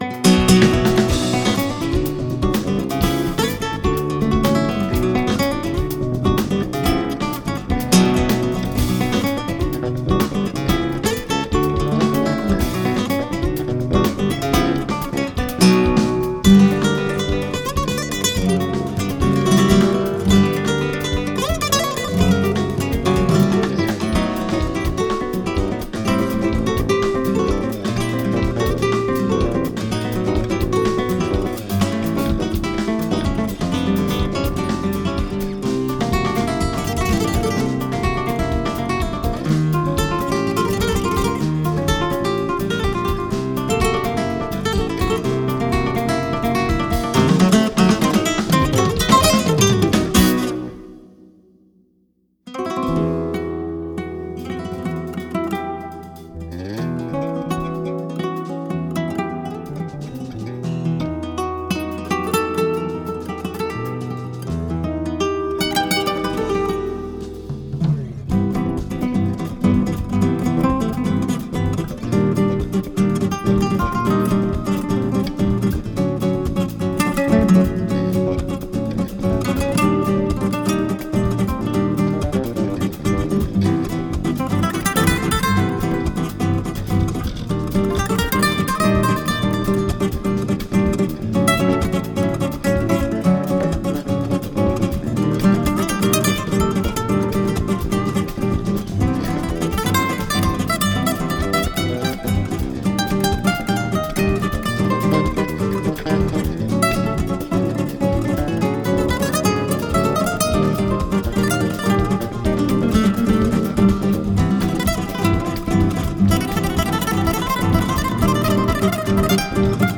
Zurück zu: Flamenco
Rumba